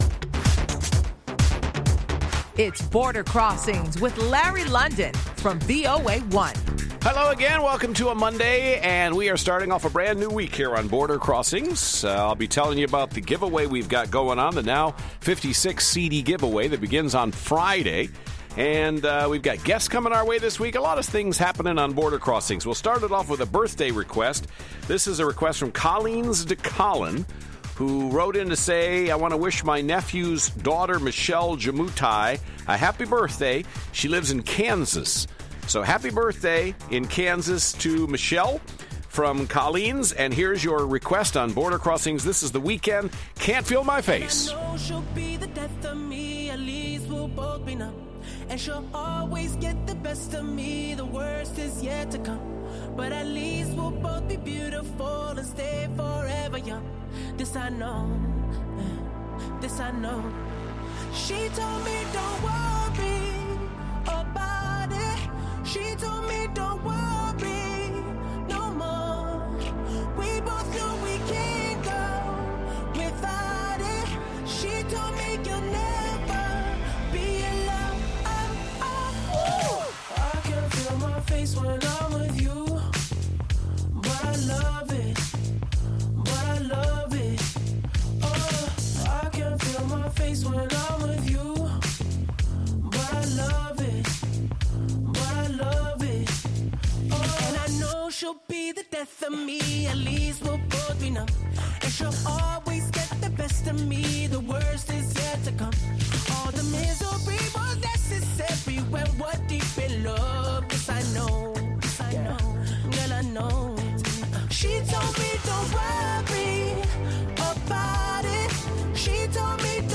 live worldwide international music request show